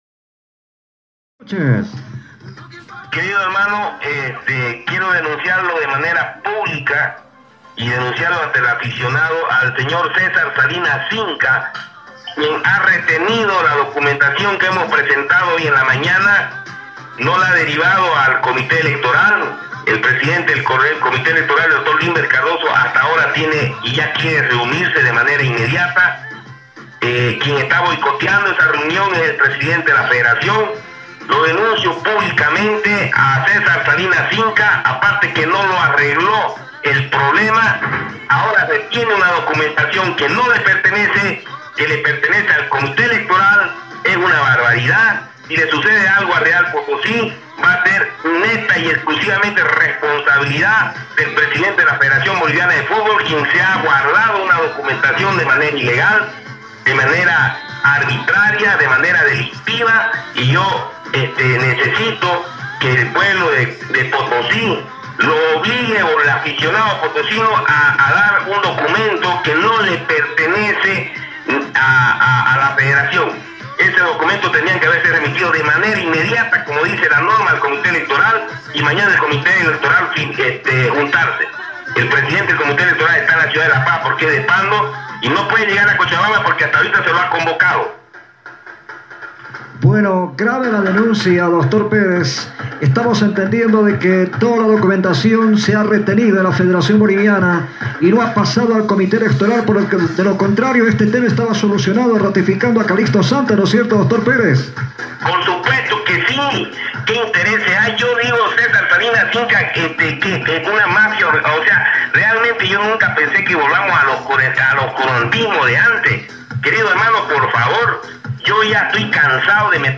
En contacto con radio Kollasuyo